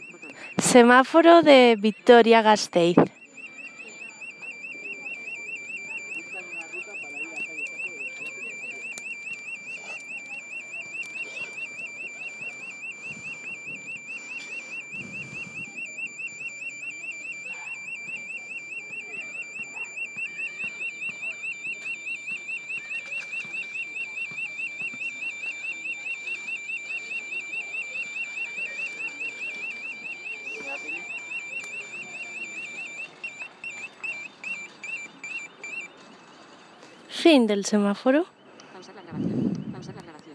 Semáforo acústico de Vitoria Gasteiz